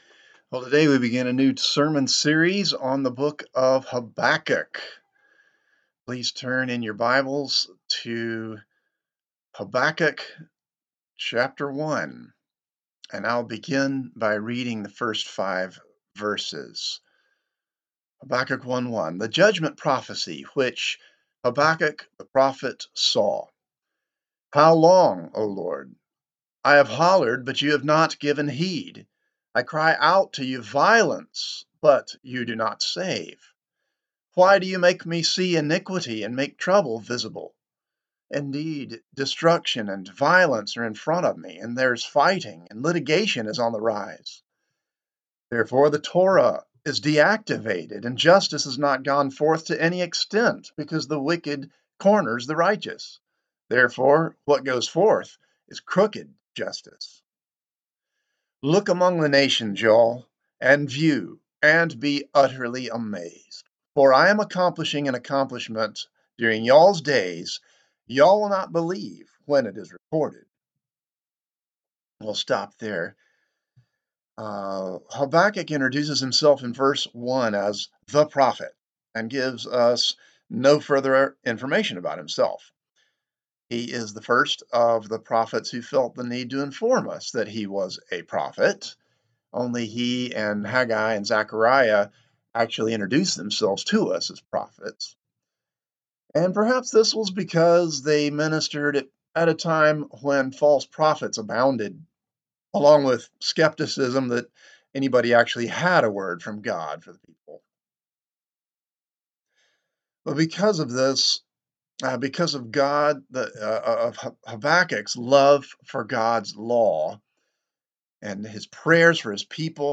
Christ the Redeemer Church | Sermon Categories Anxiety